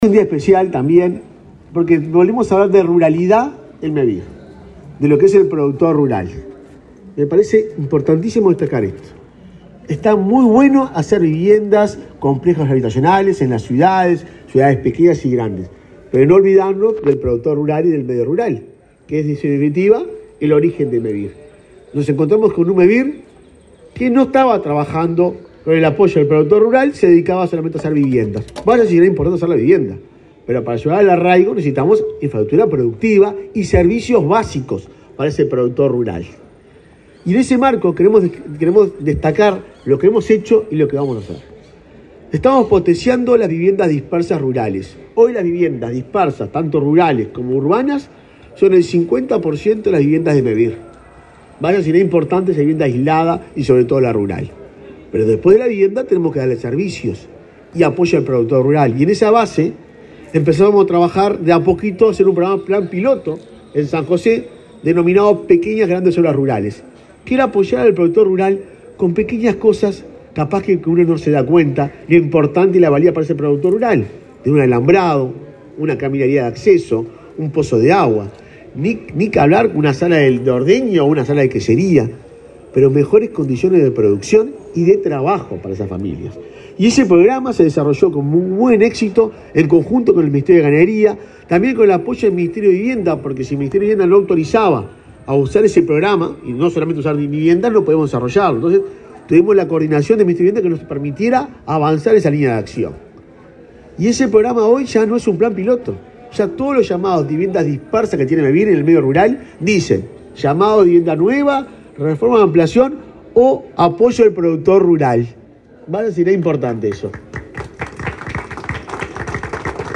Palabras del presidente de Mevir
Palabras del presidente de Mevir 14/09/2022 Compartir Facebook X Copiar enlace WhatsApp LinkedIn El presidente de Mevir, Juan Pablo Delgado, presentó en el stand de ese organismo, en la Expo Prado, diversas medidas para impulsar a pequeños emprendimientos productivos rurales.